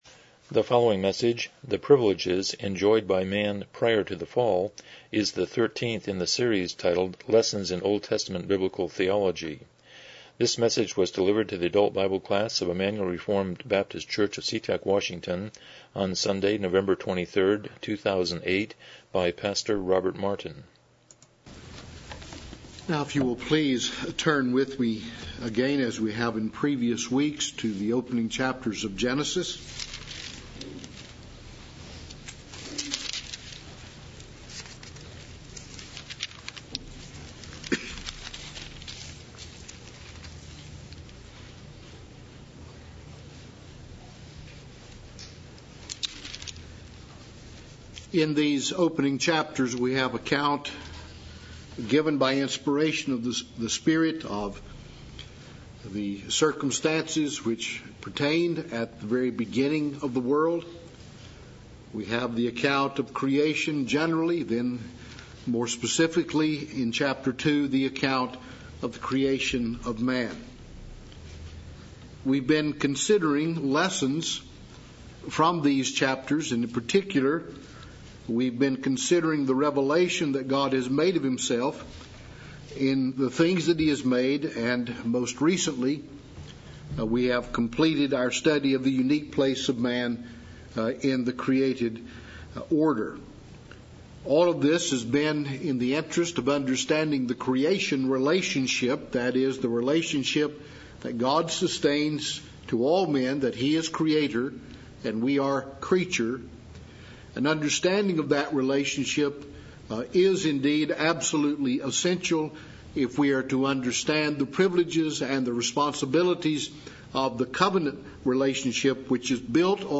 Lessons in OT Biblical Theology Service Type: Sunday School « 48 Chapter 6.5